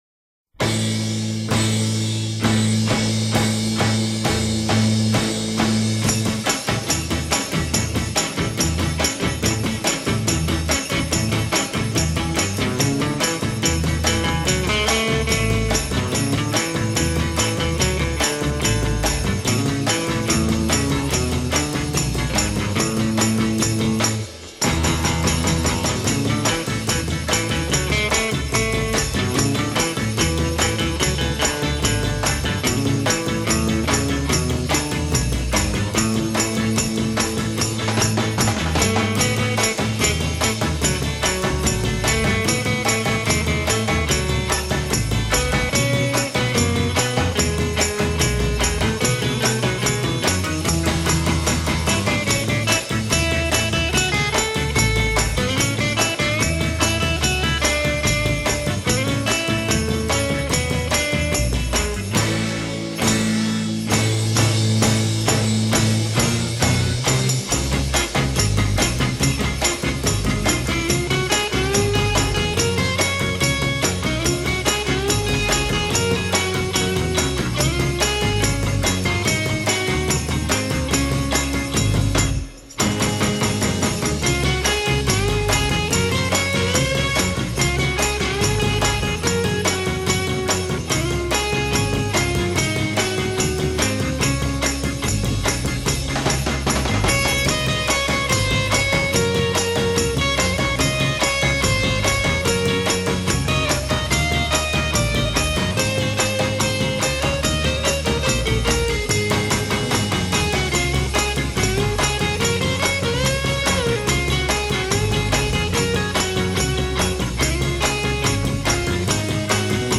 音乐类型： Pop, Christmas, Guitar　　　　  .